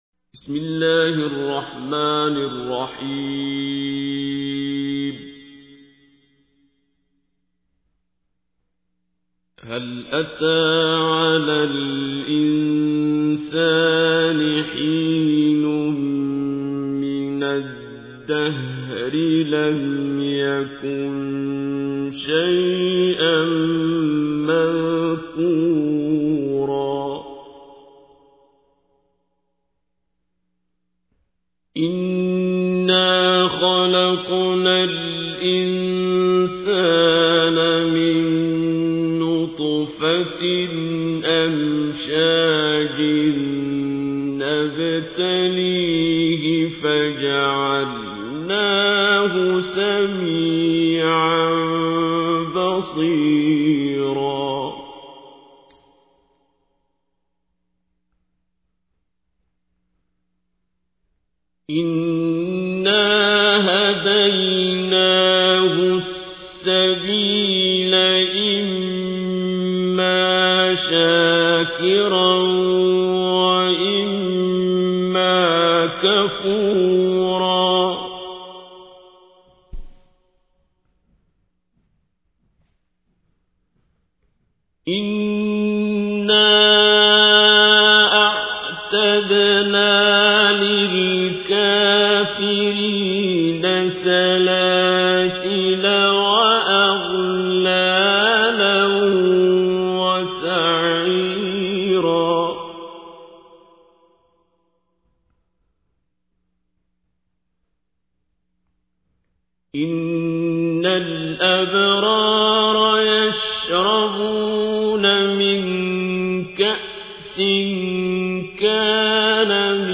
تحميل : 76. سورة الإنسان / القارئ عبد الباسط عبد الصمد / القرآن الكريم / موقع يا حسين